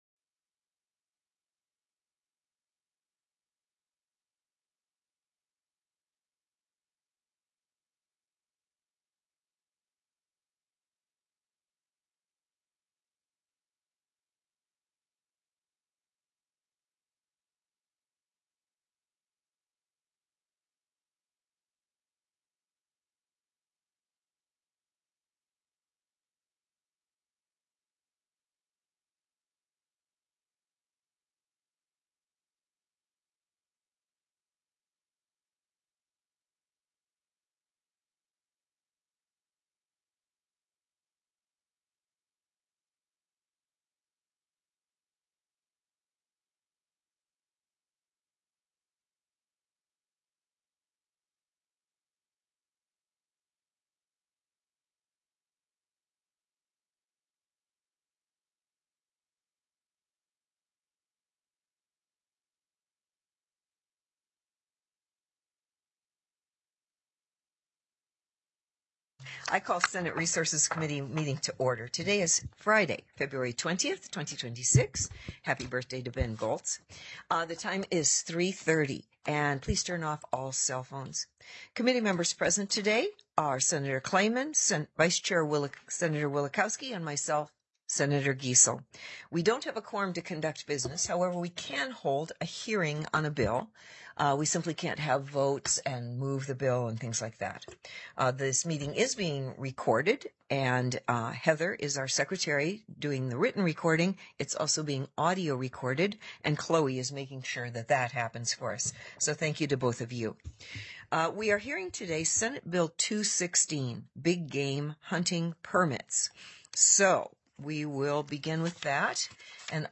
The audio recordings are captured by our records offices as the official record of the meeting and will have more accurate timestamps.
SB 216 BIG GAME HUNTING PERMITS TELECONFERENCED
Invited & Public Testimony